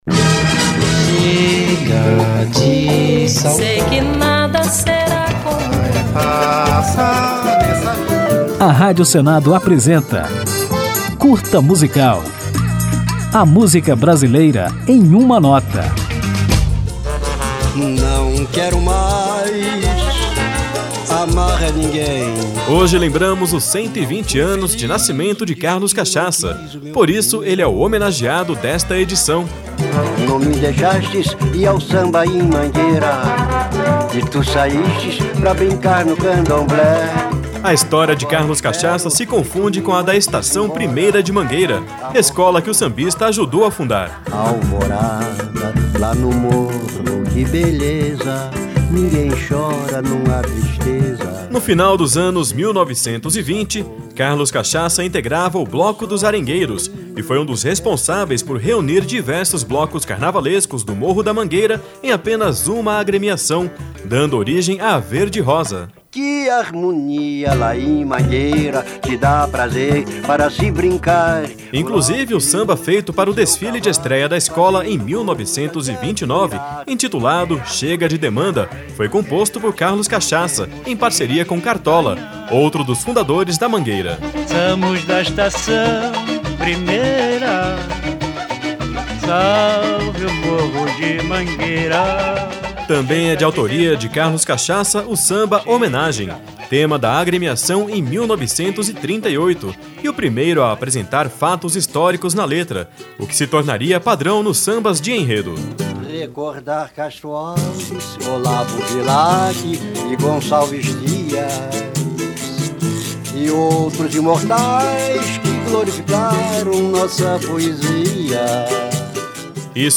Ao fim desta homenagem, vamos ouvir Carlos Cachaça na música Não Quero Mais Amar a Ninguém.
Samba